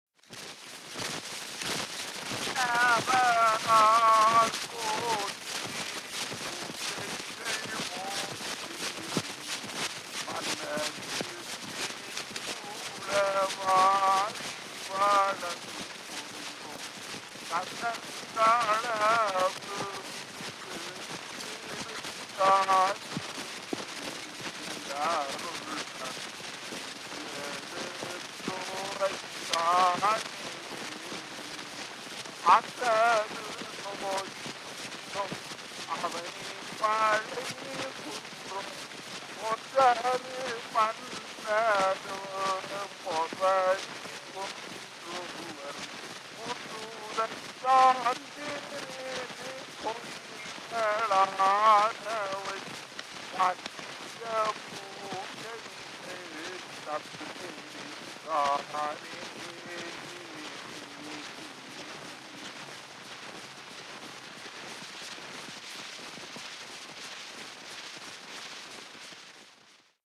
Historical sound recordings, Tamil poetry
1929, India